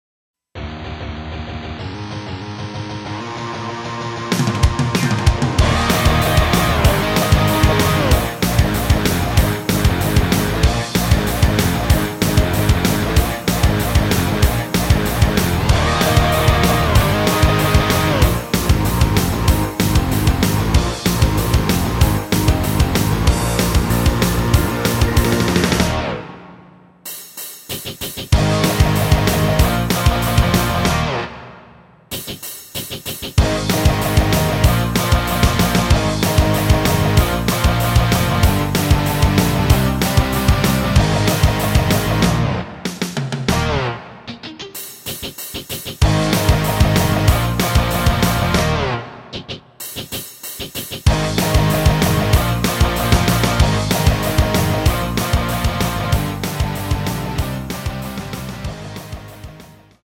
Eb
◈ 곡명 옆 (-1)은 반음 내림, (+1)은 반음 올림 입니다.
앞부분30초, 뒷부분30초씩 편집해서 올려 드리고 있습니다.